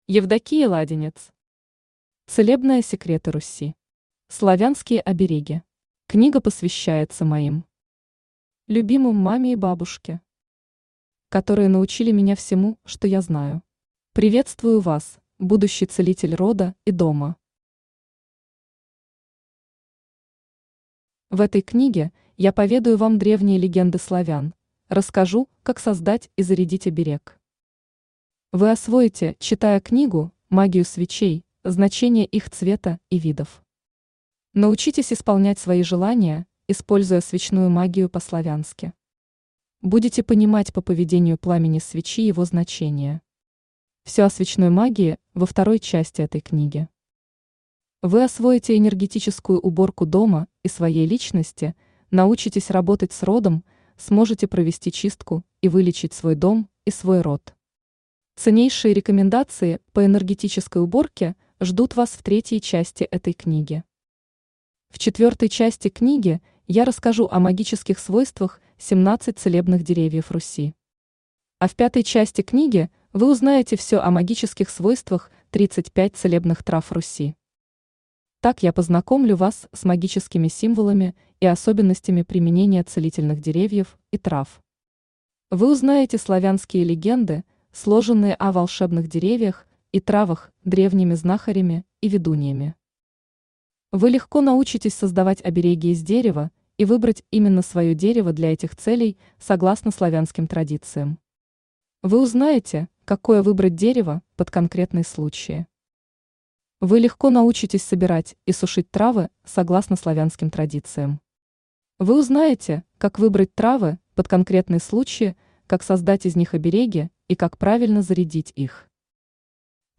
Аудиокнига Целебные секреты Руси. Славянские обереги | Библиотека аудиокниг
Славянские обереги Автор Евдокия Ладинец Читает аудиокнигу Авточтец ЛитРес.